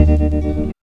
3004L GTRCHD.wav